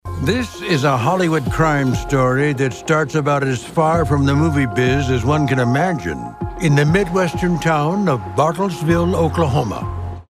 The program is narrated by Stacy Keach Jr., who mentions the town.